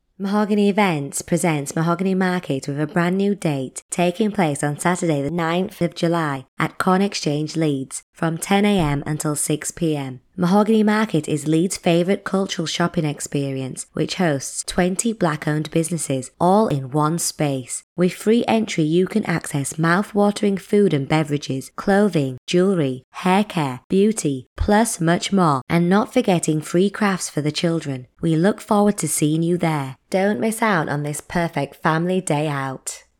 Female
English (British)
Adult (30-50)
My voice style is a northern husky, raspy tone, which is a little sultry and calming at the same time. I have been told on many occasions that I sound like the former Spice Girls band member Melanie B. My professional voice is more of a professional and softer tone.
Urban
Radio Event Advert